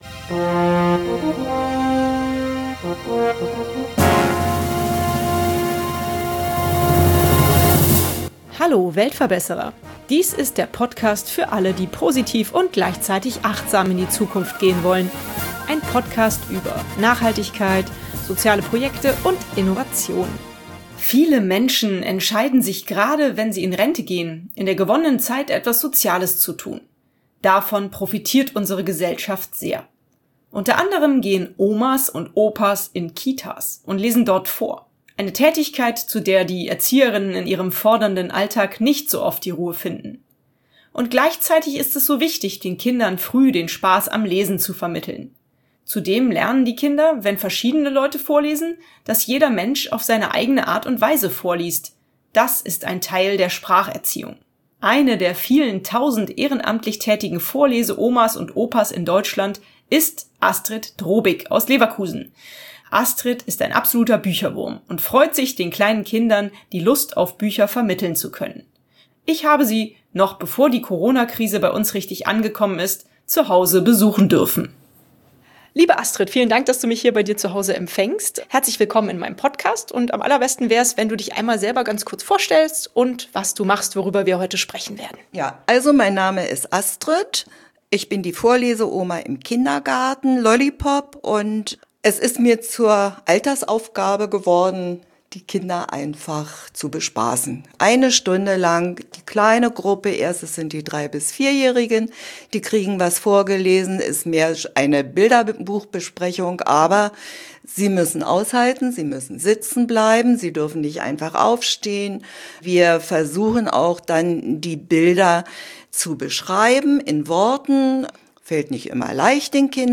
Ich habe sie noch bevor die Corona-Krise richtig bei uns angekommen ist, zuhause besuchen dürfen.